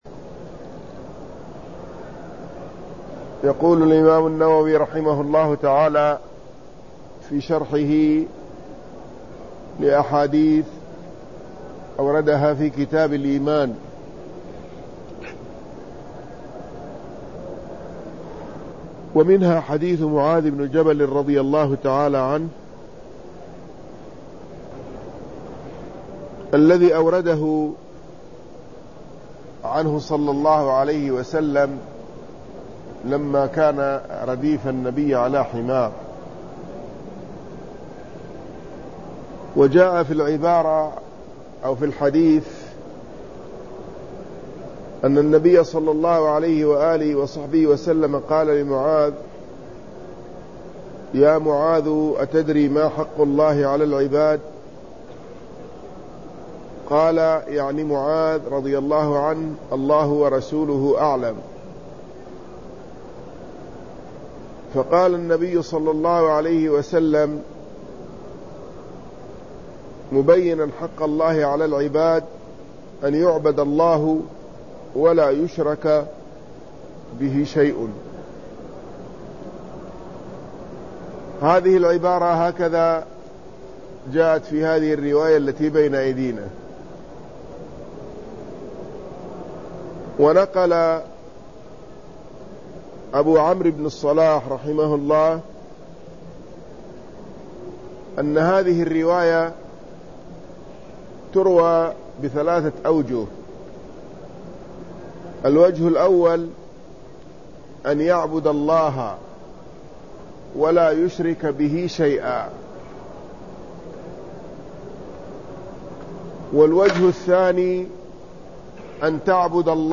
شرح صحيح مسلم